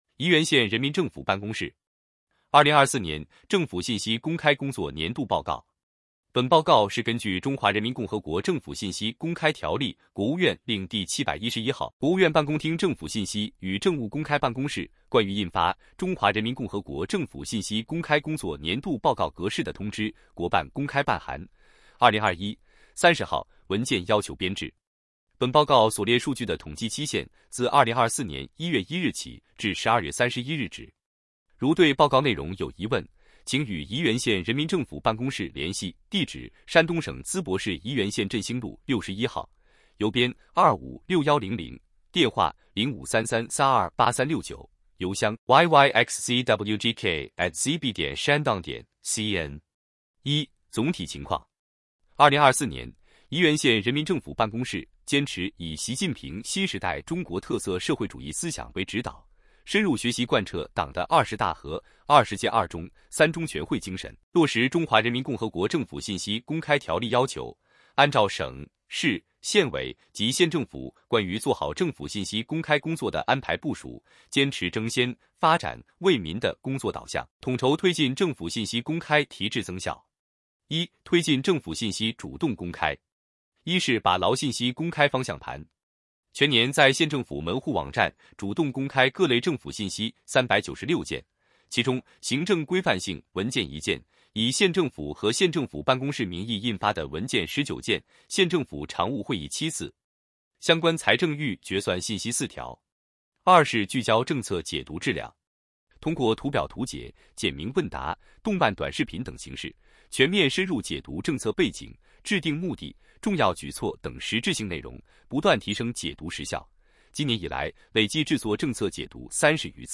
语音播报         |           电子书